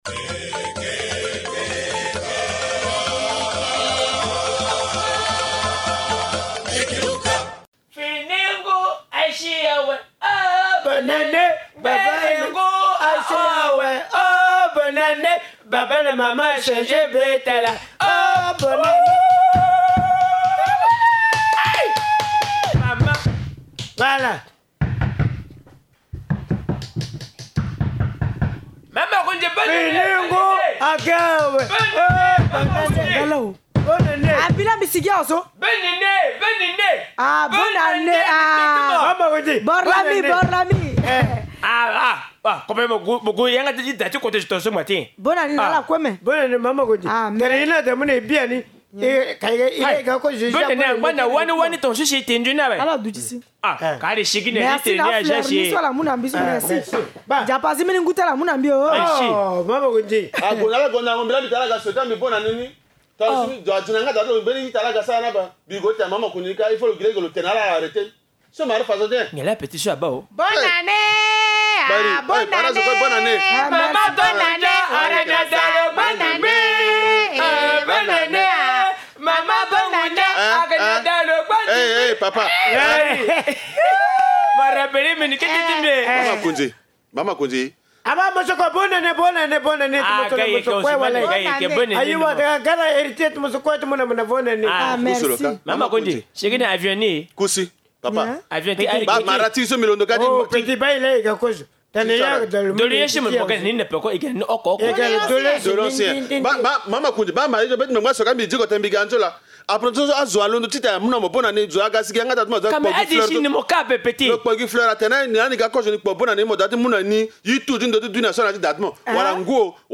Linga Théâtre: A Linga, les notables exhortent les habitants à celebrer dignement le nouvel an